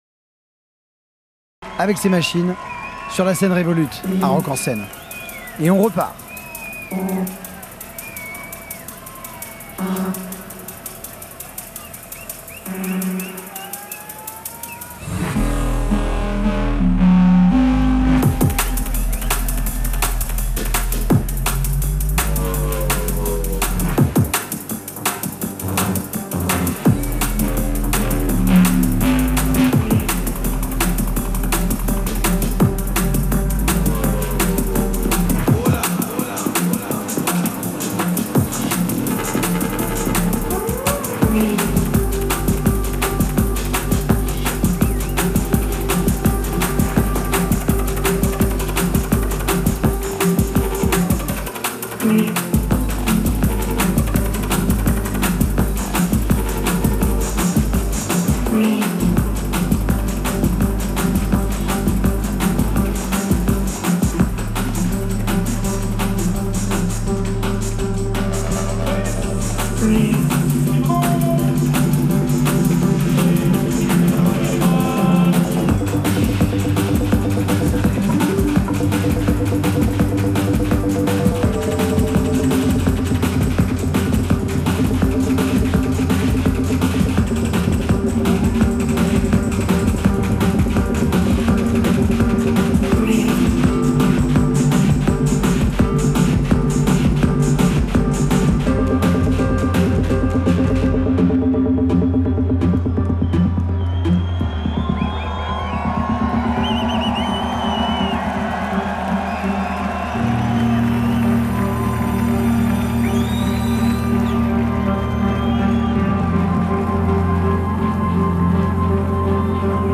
recorded live on August 23 this year